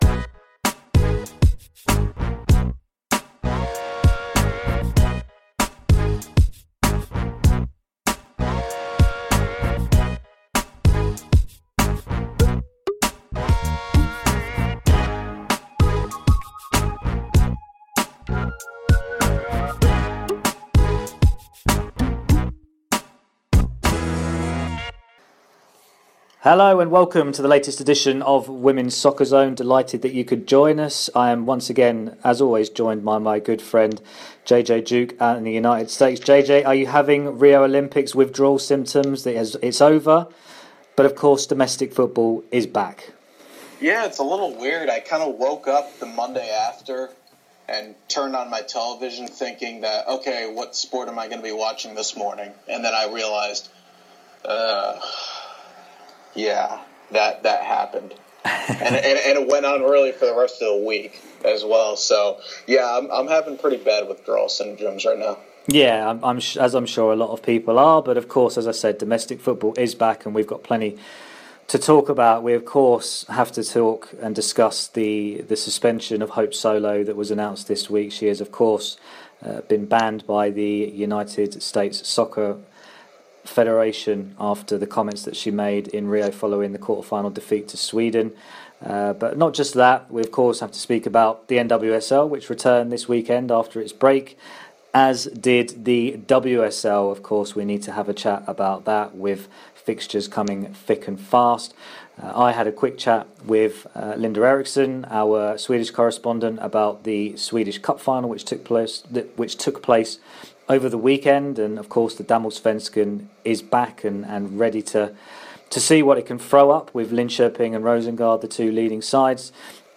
We also have interviews with previous guest and Rio silver medallist, Kosovare Asllani, and making her debut on the show to talk about a new project (and soccer of course), Canada international, Erin McLeod.